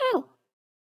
Player Hurt.wav